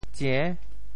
How to say the words 上 in Teochew？
tsie~6.mp3